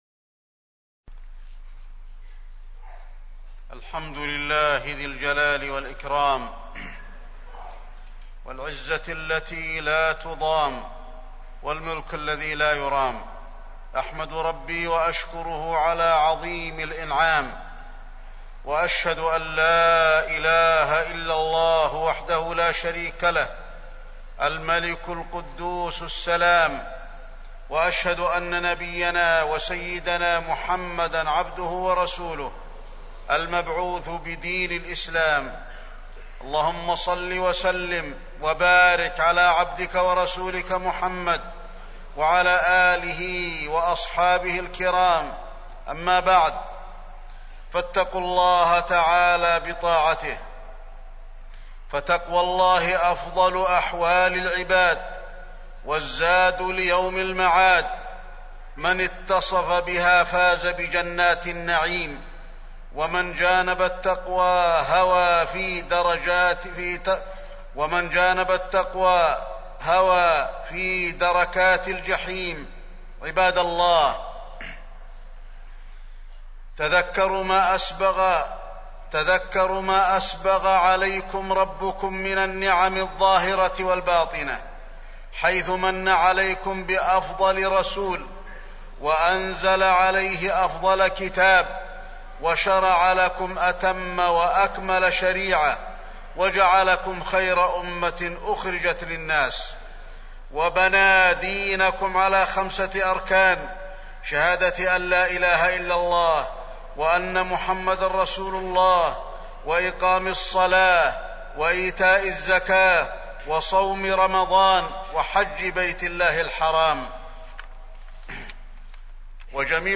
تاريخ النشر ٢٦ شعبان ١٤٢٦ هـ المكان: المسجد النبوي الشيخ: فضيلة الشيخ د. علي بن عبدالرحمن الحذيفي فضيلة الشيخ د. علي بن عبدالرحمن الحذيفي استقبال شهر رمضان